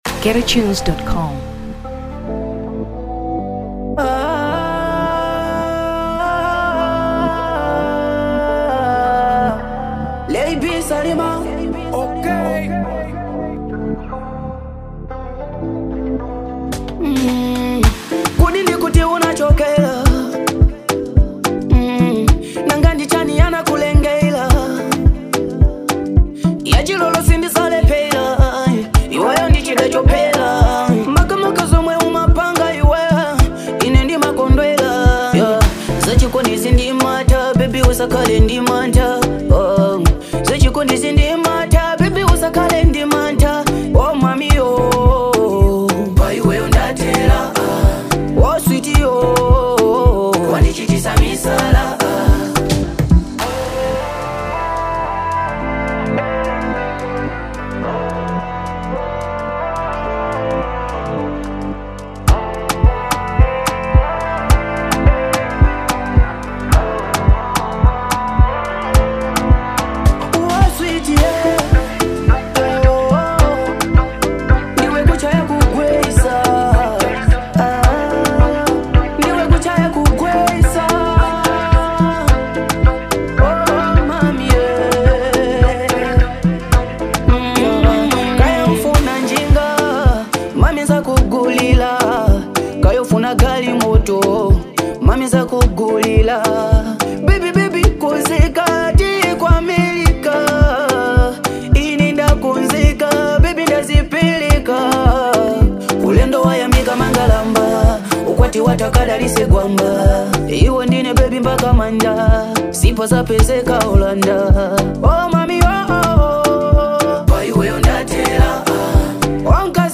Afro 2023 Malawi